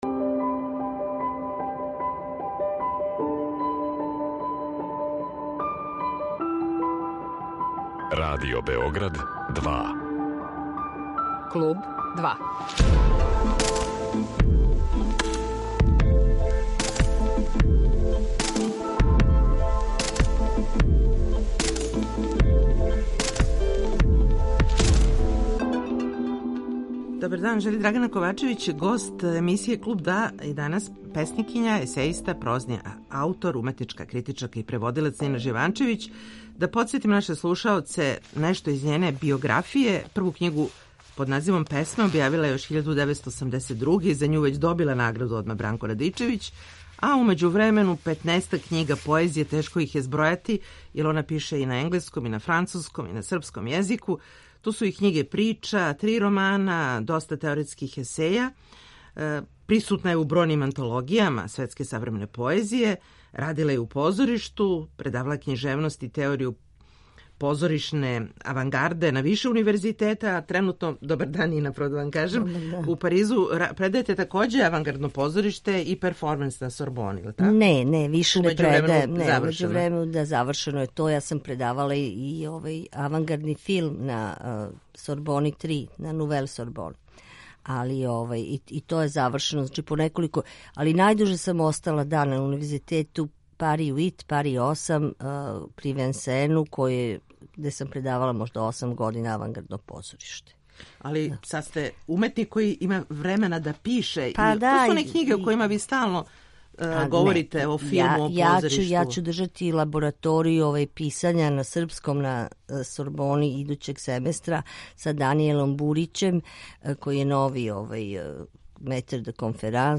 Гост емисије